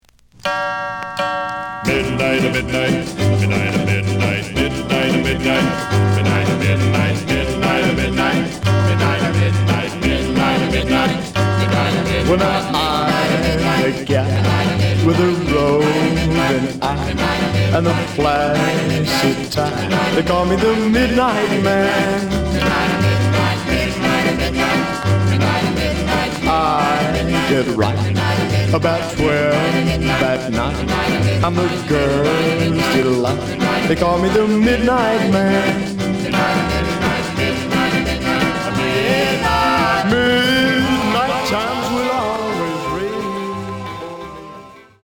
The audio sample is recorded from the actual item.
●Genre: Rhythm And Blues / Rock 'n' Roll
Some click noise on B side due to scratches.